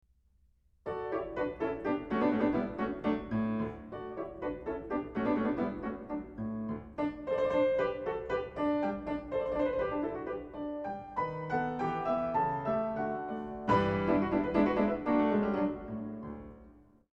Parallelle sekstakkorder